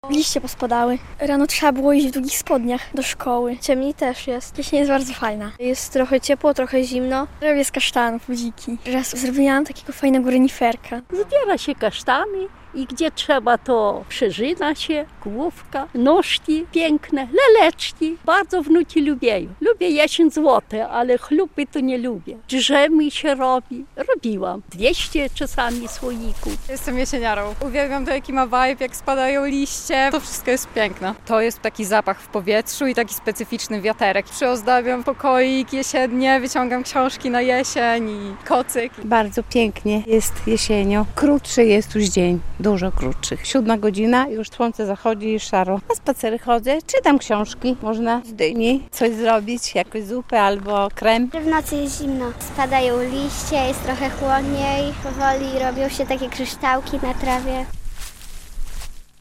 Liście opadają, powoli robi się chłodniej, a rano są takie kryształki na trawie - mówią młodzi białostoczanie.